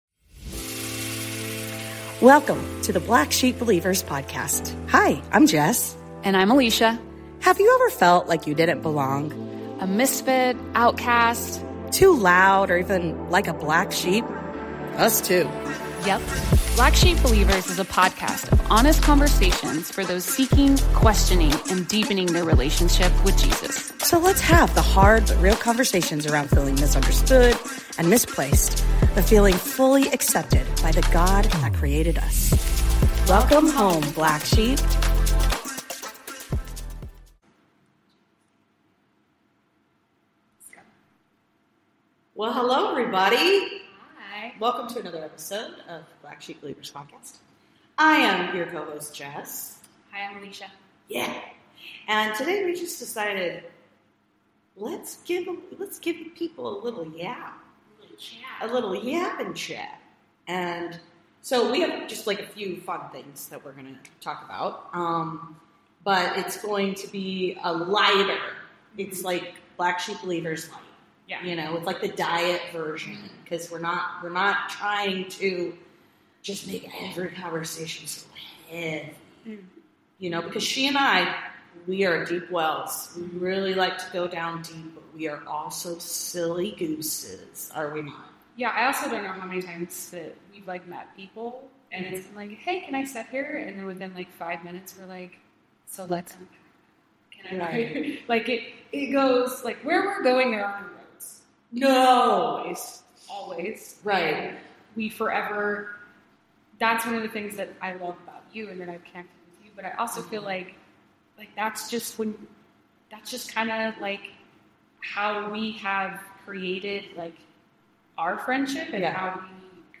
In this episode of the Black Sheep Believers Podcast, we enjoyed a light-hearted yet profound conversation about friendship, personal growth, and self-discovery as we approach our 40s. We explore themes of dying to oneself, the importance of compassion and forgiveness, and embracing new habits and interests—a silly mix of humorous anecdotes and personal stories, creating a relatable and engaging atmosphere that we hope you enjoy, too!